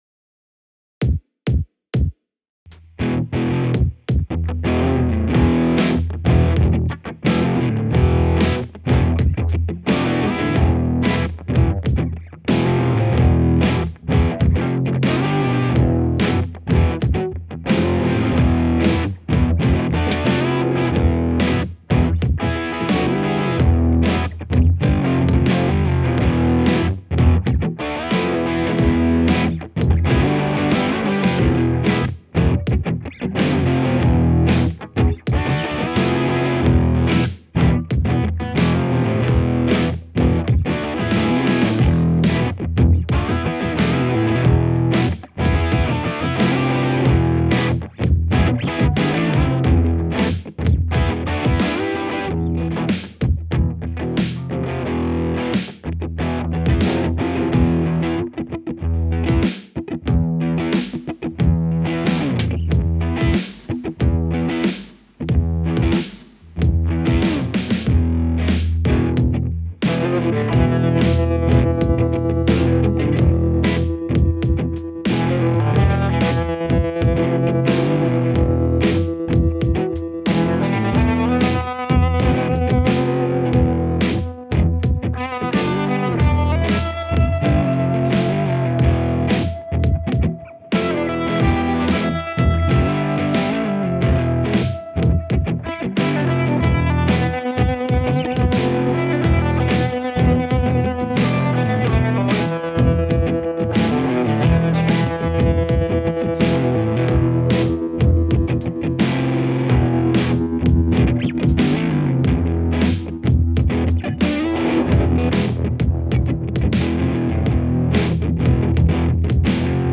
Eigentlich wollte ich nur ein Gitarrenriff festhalten, und dann hat jammen irgendwie Spaß gemacht und dann hab ich doch Stunden umhergebastelt: [hier sollte eigentlich der Mediaplayer kleben.
Das sogenannte Mixing ist nämlich kaum vorhanden, Drums haben schon etwas EQ und Compression abbekommen, Gitarren allerdings nur ein bisschen HighPass.